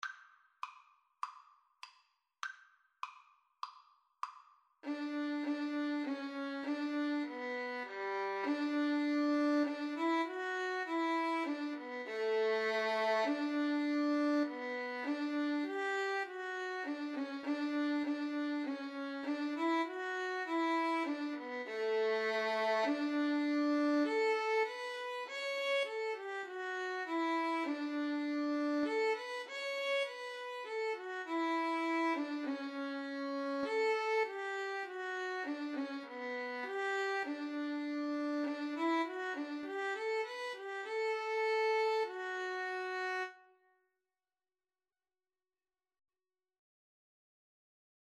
Free Sheet music for Violin Duet
D major (Sounding Pitch) (View more D major Music for Violin Duet )
4/4 (View more 4/4 Music)